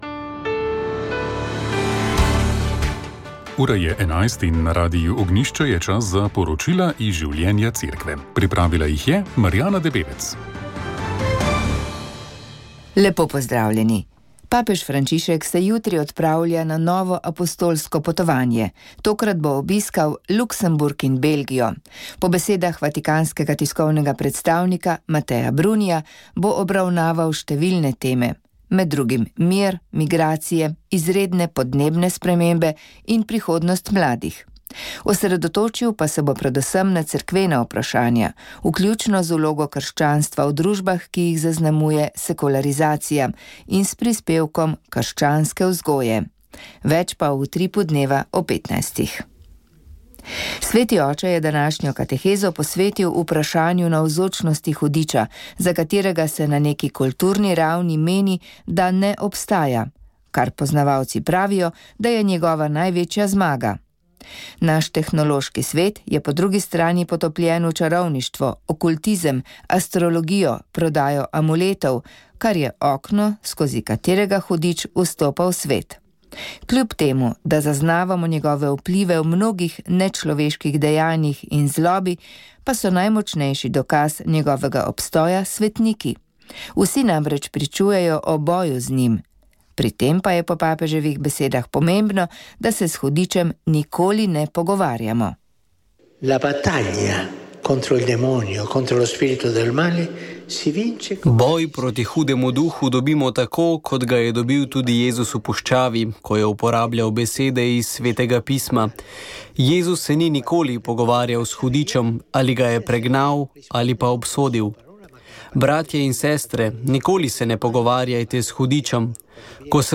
Komentar tedna